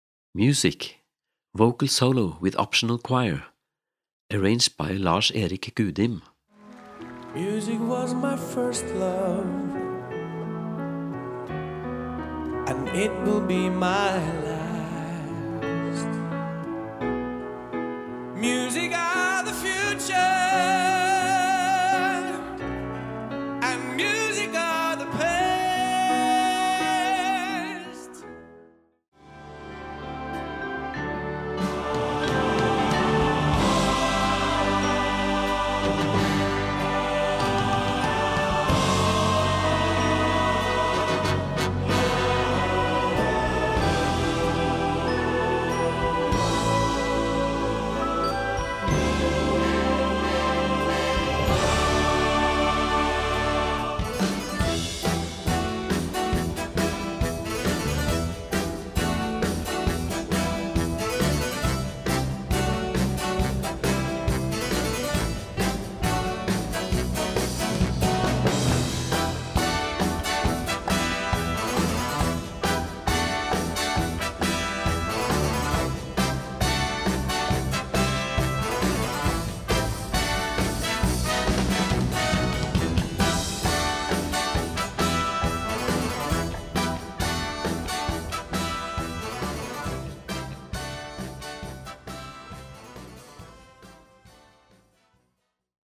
für Solo Gesang oder Chor und Blasorchester
Besetzung: Blasorchester